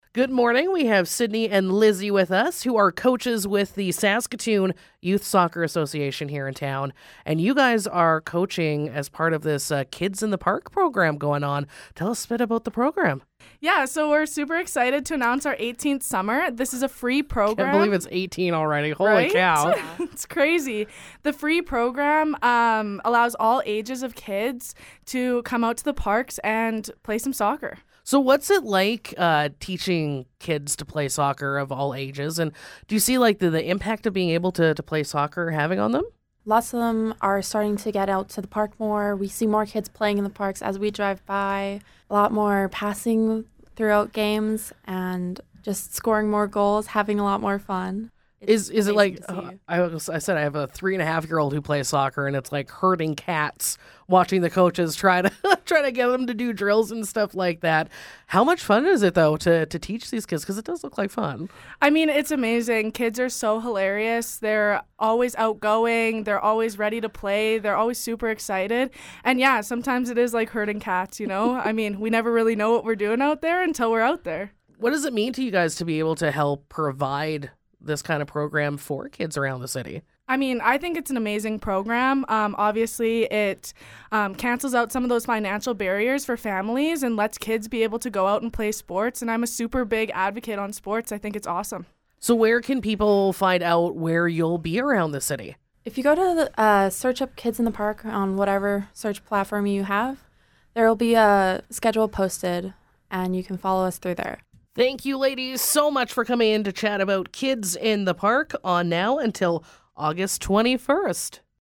Interview: Kids in the Park Soccer Program